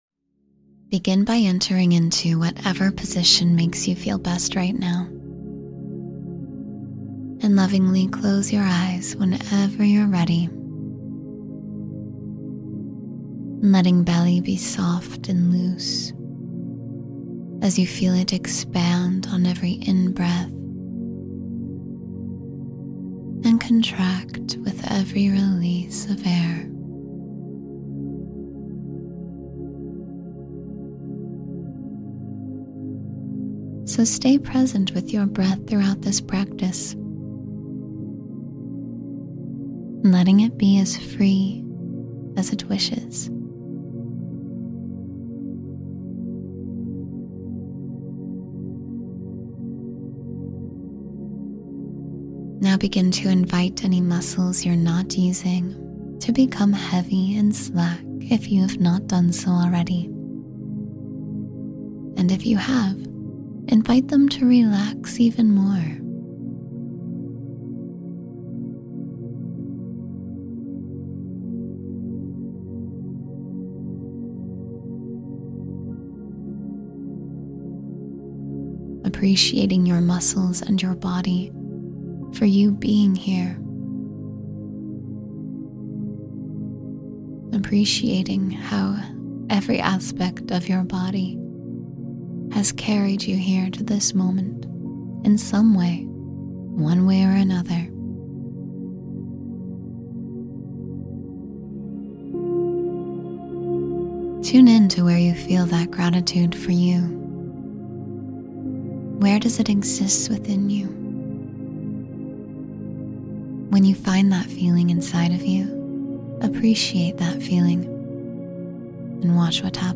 Meet and Connect with Your Spirit Animal — Meditation for Inner Wisdom